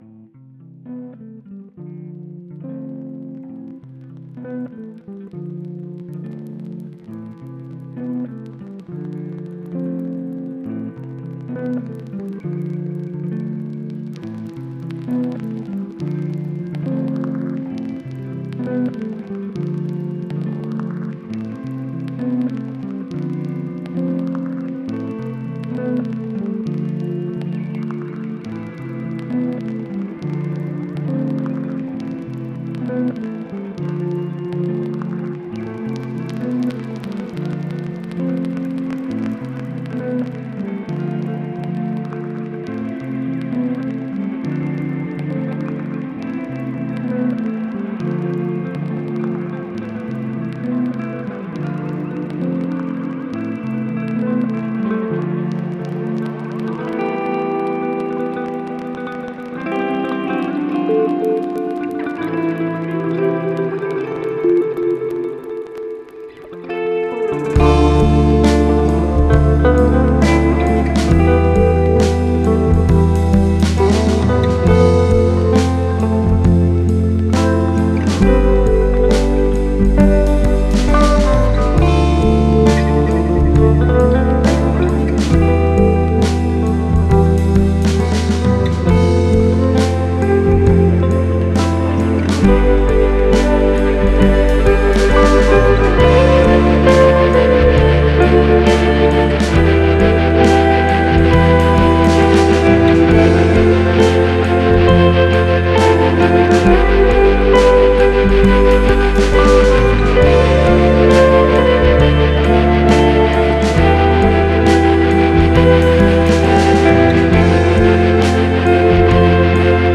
موسیقی بیکلام }